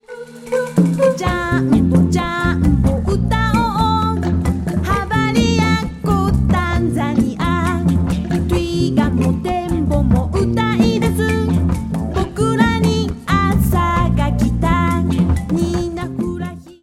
北海道の帯広に当時できたばかりのスタジオでレコーディング